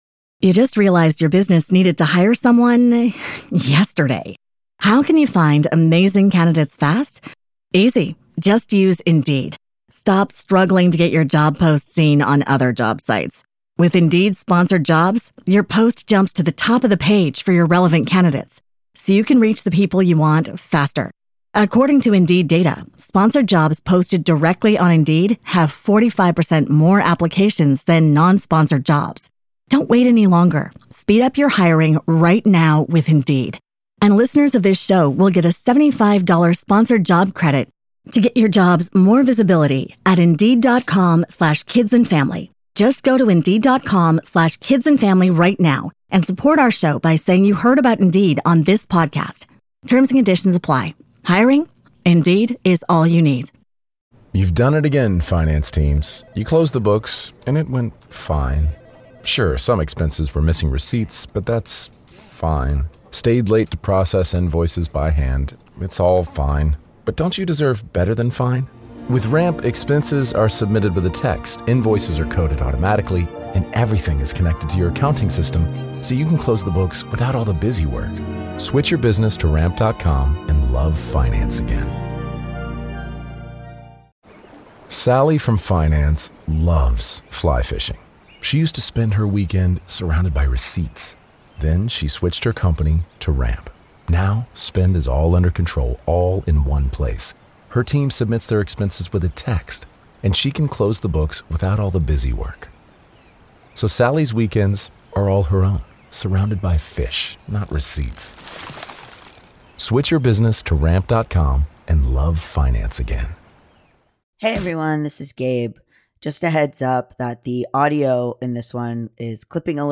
It's a very comforting conversation with lots of great tips and advice!